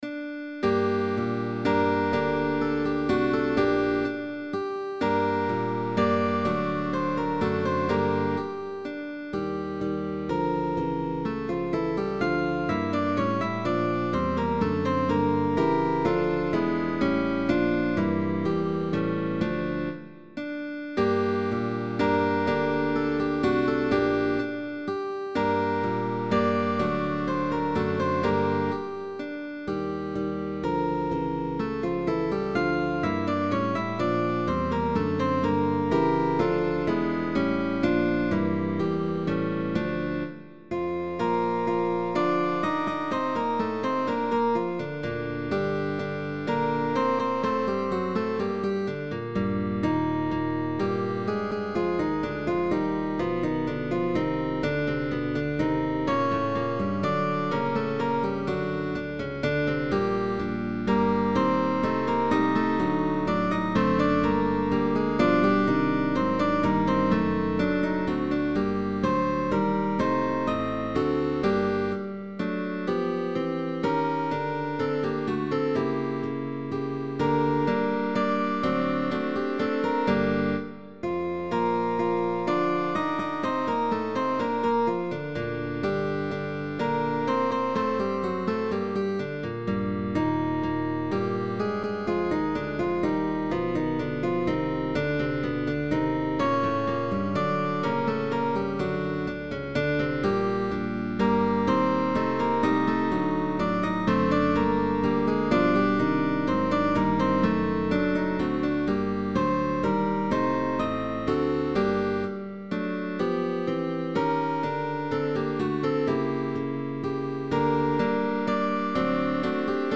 With bass optional.
GUITAR QUARTET
Tag: Classicism